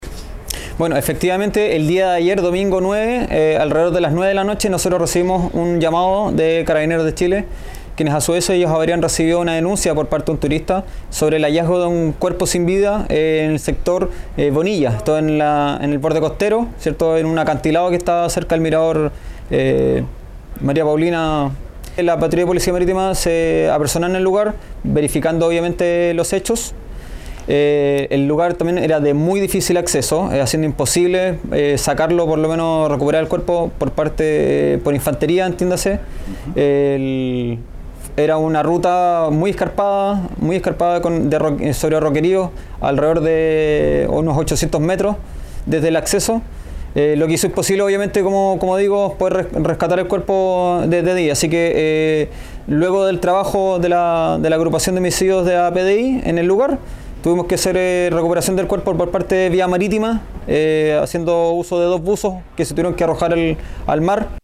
Por ello es que hubo activación de equipos de emergencia y especializados en este tipo de operativos, señaló el capitán de Puerto de Ancud, Sebastián Gysling.